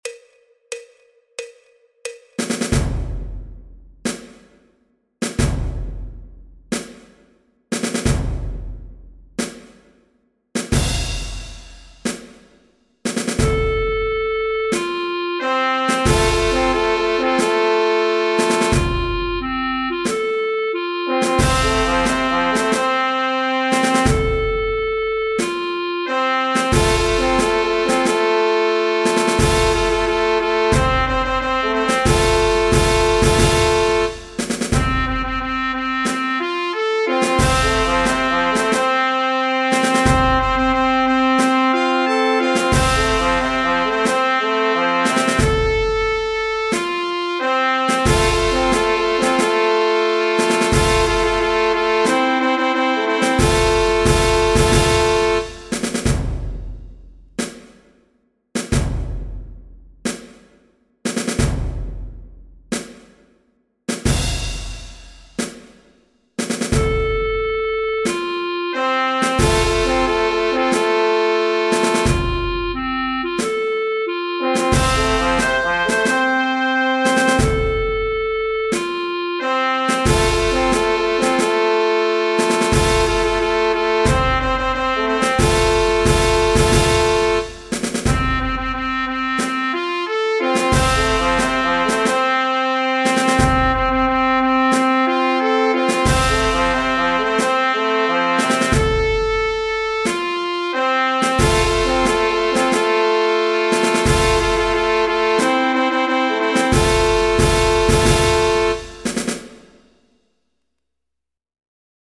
Clarinete en Sol Mayor (G) Marchas de Procesión
El MIDI tiene la base instrumental de acompañamiento.
Música clásica